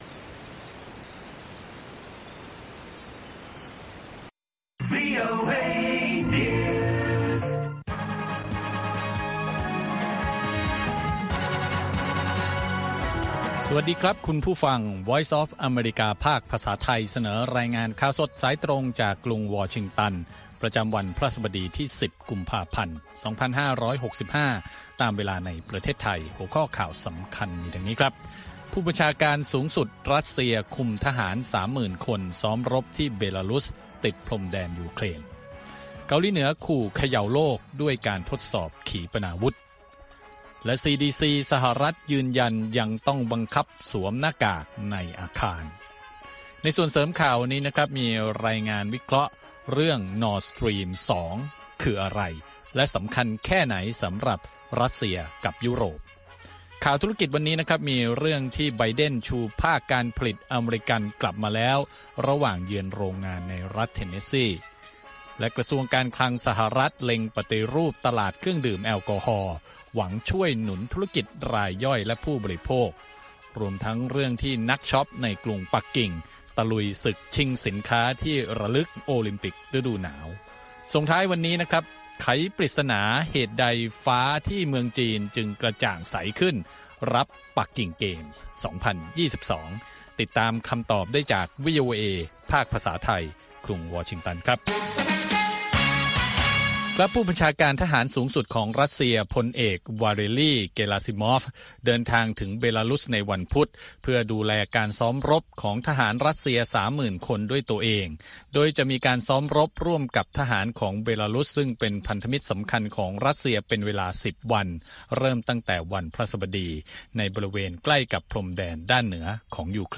ข่าวสดสายตรงจากวีโอเอ ภาคภาษาไทย 8:30–9:00 น. ประจำวันพฤหัสบดีที่ 10 กุมภาพันธ์ 2565 ตามเวลาในประเทศไทย